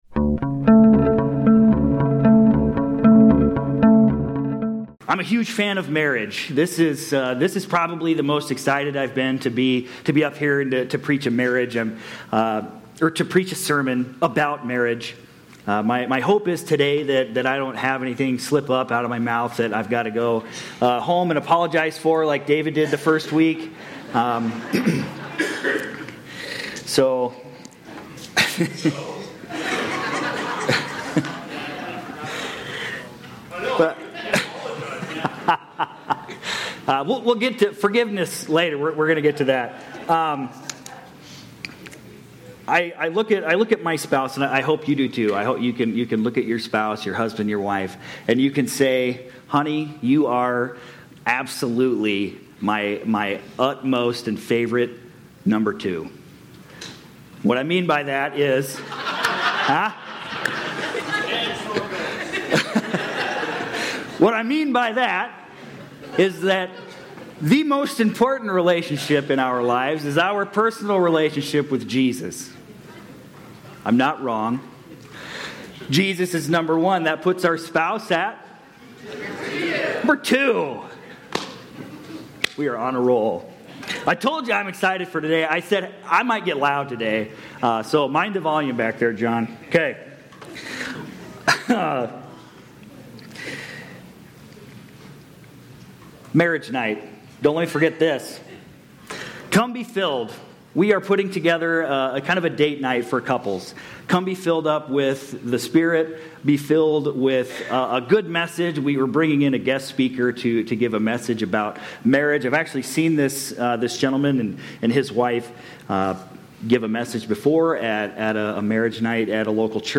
Sept-21-25-Sermon-Audio.mp3